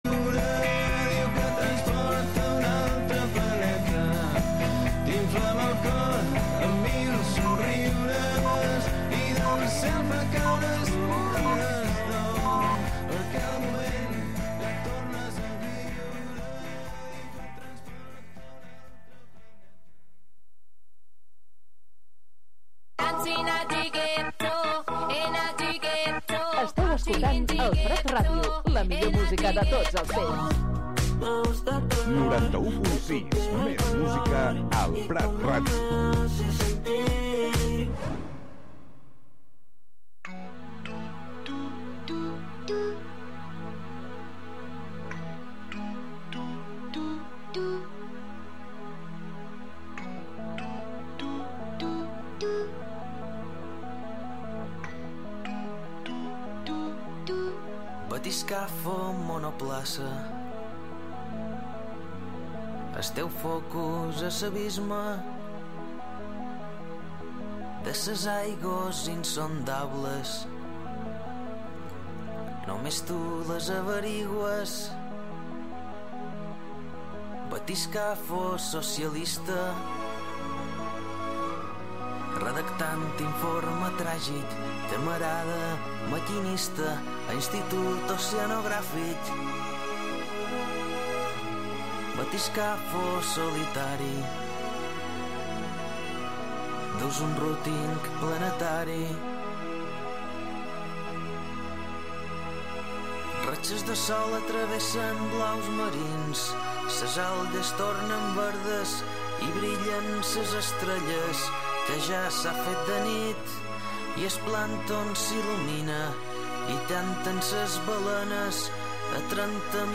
Recorreguts musicals pels racons del planeta, música amb arrels i de fusió.